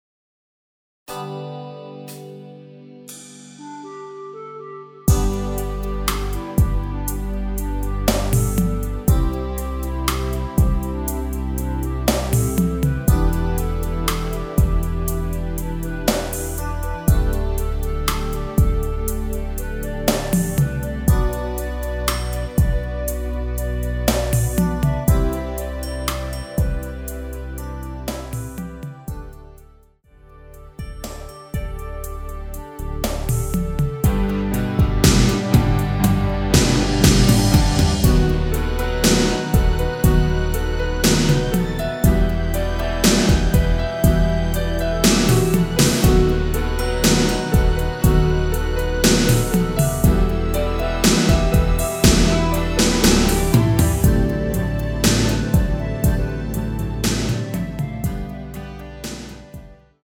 전주 없이 시작 하는곡이라 전주 1마디 만들어 놓았습니다.(미리듣기 확인)
원키에서(-6)내린 멜로디 포함된 MR입니다.(미리듣기 확인)
앞부분30초, 뒷부분30초씩 편집해서 올려 드리고 있습니다.